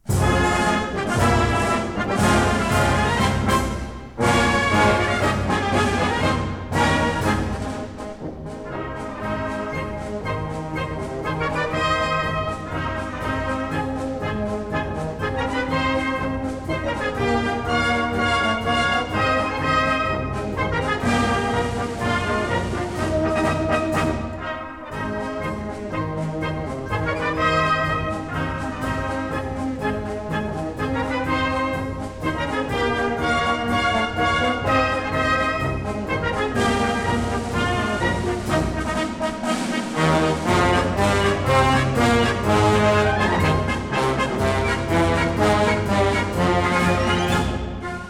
A 1959 stereo recording